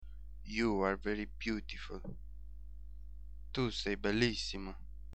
Tags: music tune song fun